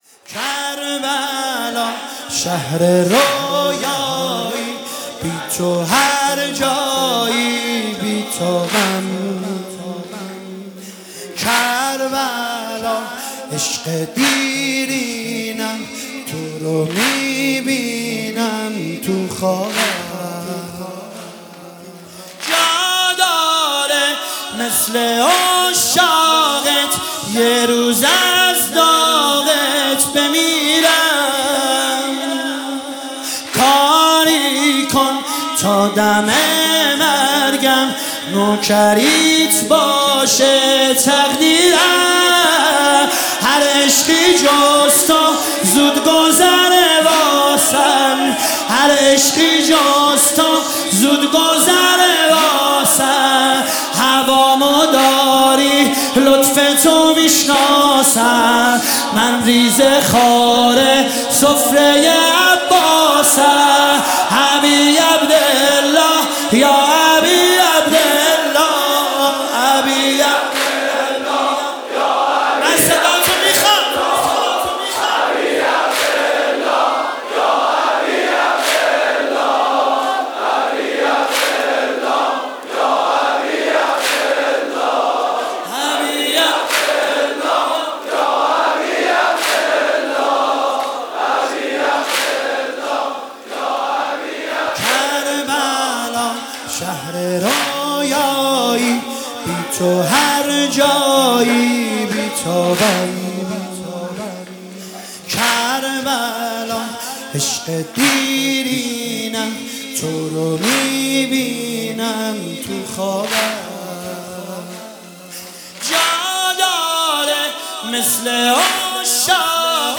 مداحی واحد
فاطمیه دوم 1403